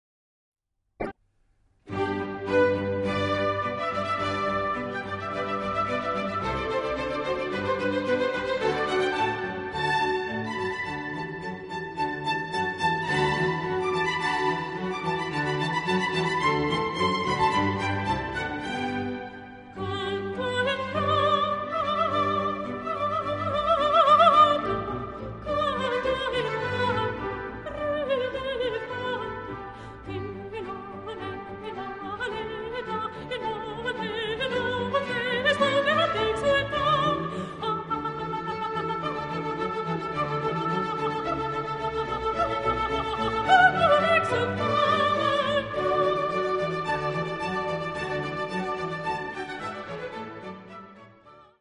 Genre-Style-Forme : Sacré ; Baroque
Type de choeur : SATB  (4 voix mixtes )
Instruments : Violon (1)
interprété par Estonian Philharmonic Chamber Choir dirigé par Tonu Kaljuste